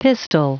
Prononciation du mot pistol en anglais (fichier audio)
Prononciation du mot : pistol